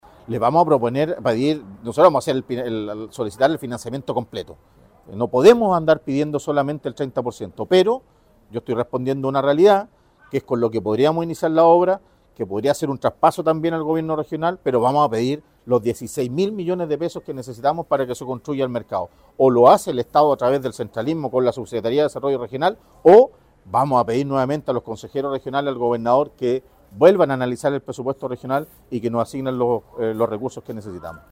Mercado-Talcahuano-5-Alcalde-Talcahuano-3.mp3